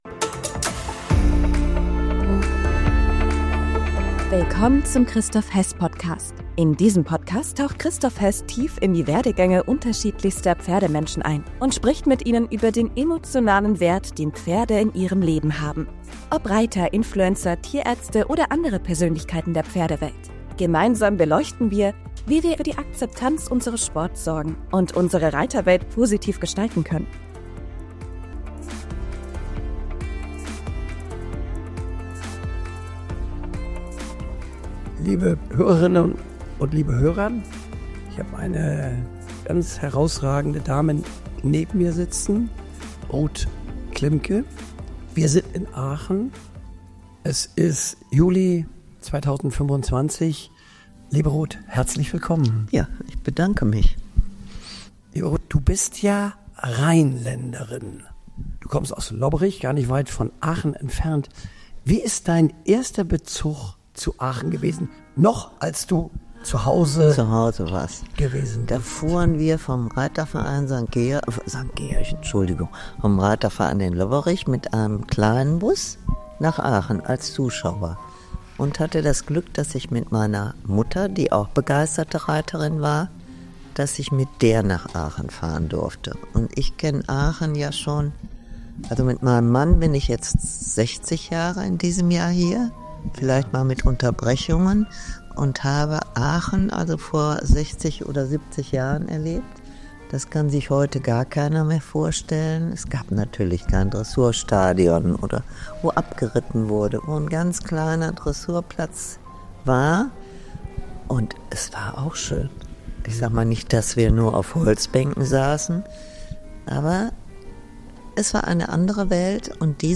Ein sehr persönliches, inspirierendes Gespräch, das weit über den Pferdesport hinausgeht.